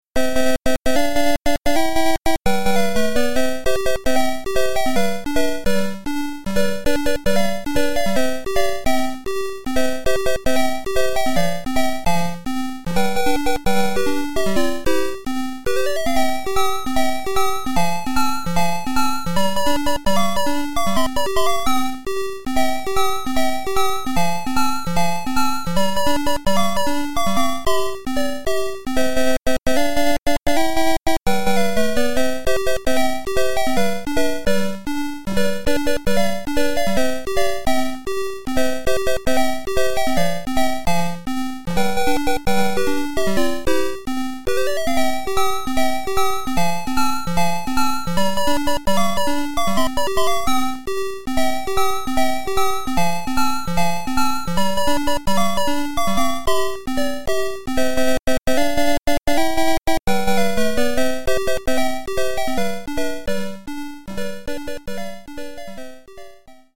irritatingly catchy